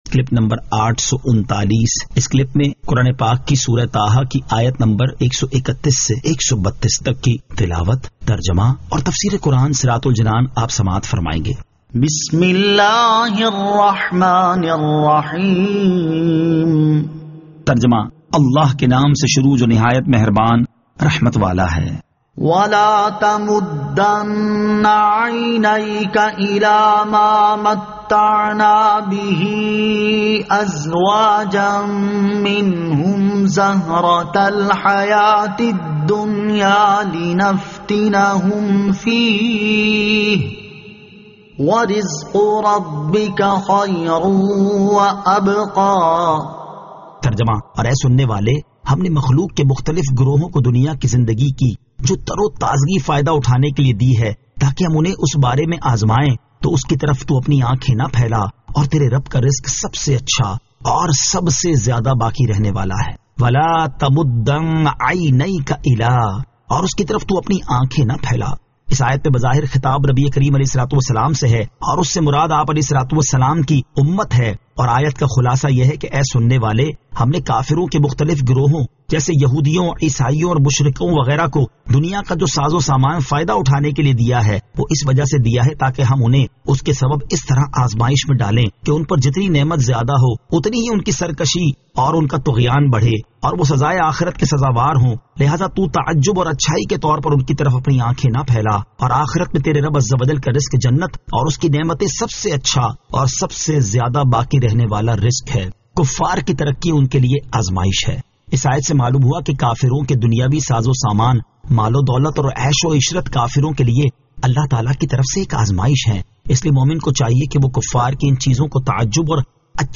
Surah Taha Ayat 131 To 132 Tilawat , Tarjama , Tafseer